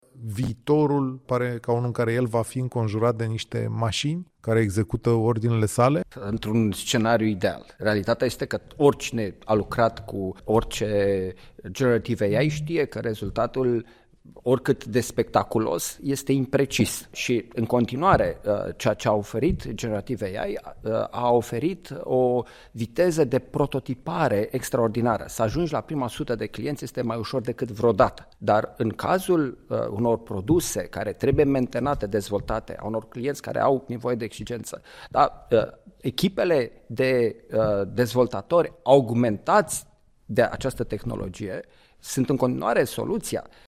specialist în inteligență artificială, în dialog